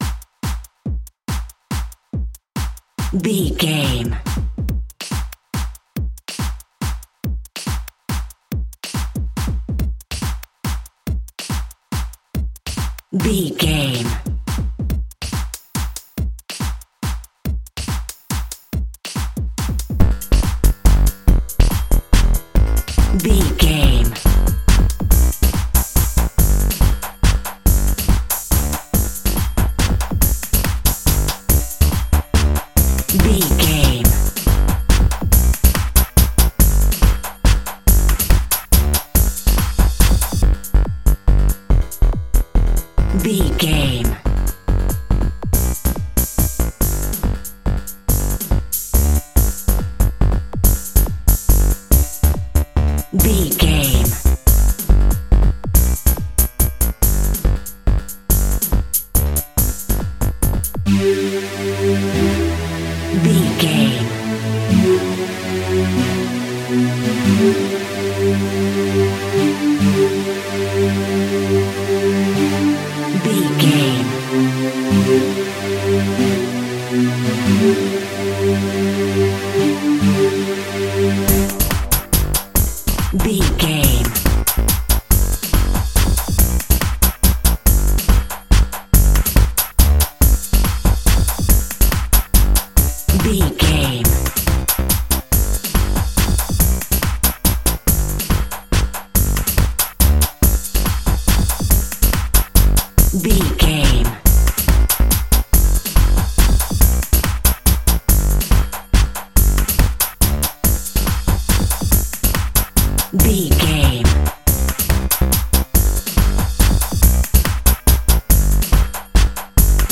House Waltz Fusion Music.
Fast paced
Aeolian/Minor
Fast
dark
futuristic
driving
energetic
tension
synthesiser
drum machine
percussion
electro house